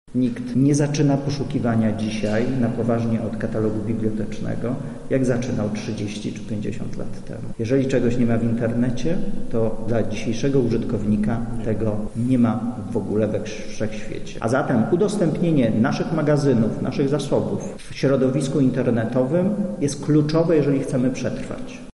O tym jak ważna jest to zmiana mówi dyrektor Biblioteki Narodowej Tomasz Markowski: